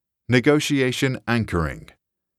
[ni-goh-shee-ey-shuh n] [ang-kering]